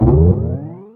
1 channel
BOUNCE.mp3